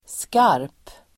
Uttal: [skar:p]